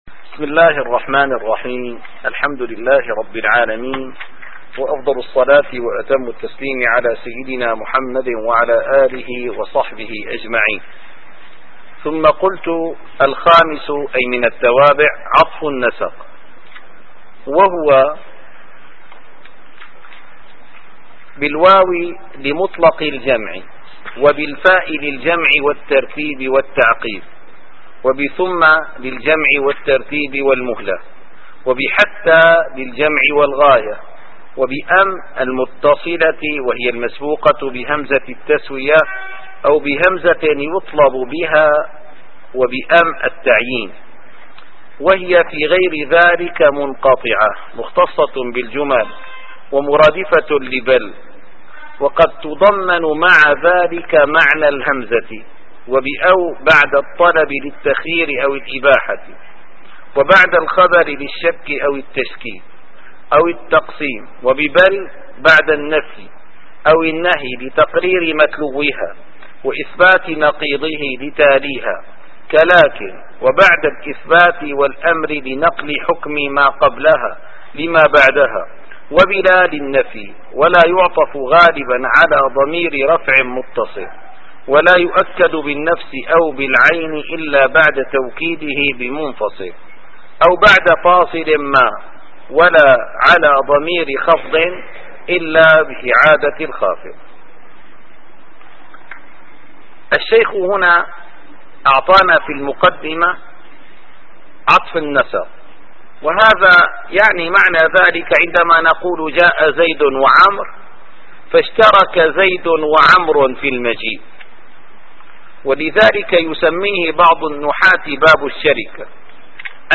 - الدروس العلمية - شذور الذهب في معرفة كلام العرب - الخامس عطف النسق ص579-588.